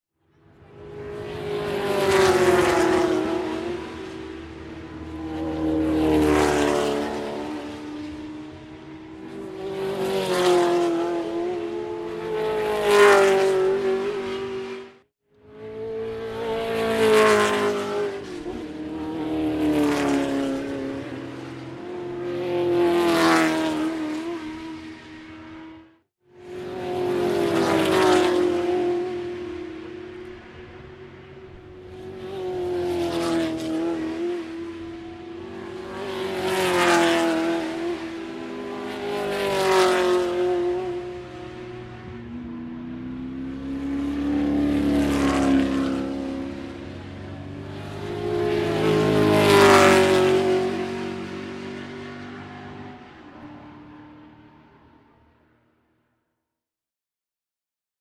Ford GT 40 ziehen im Rennen um die Whitsun Trohpy am Goodwood Revival 2013 vorbei
Ford_GT_40.mp3